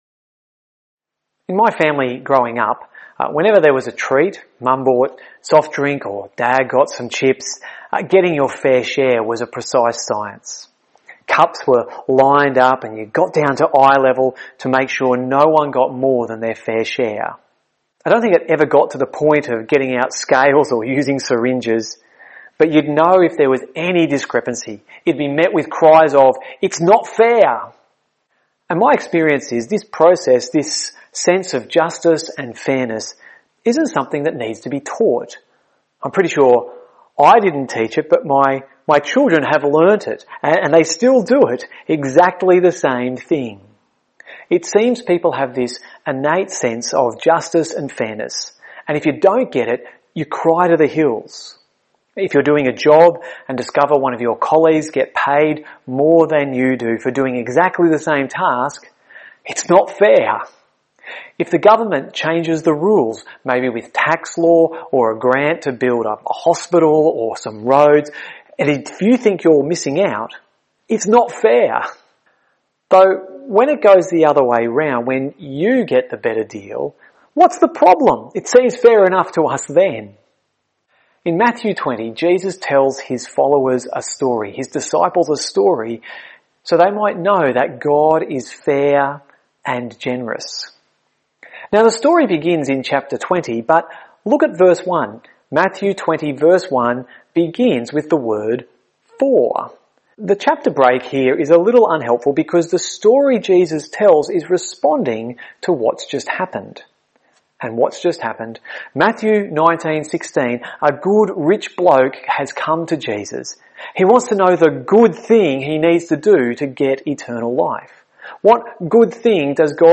Weekly sermon podcast from Gympie Presbyterian Church